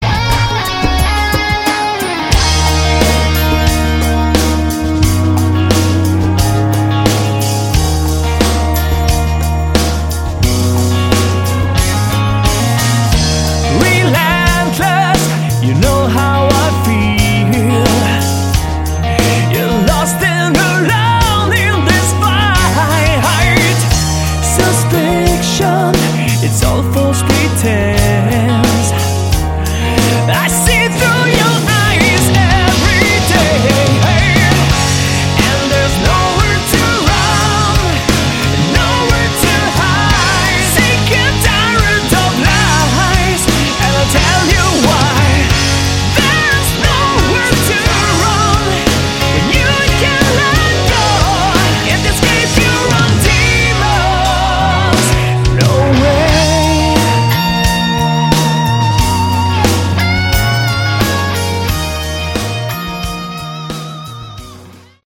Category: Melodic Rock / AOR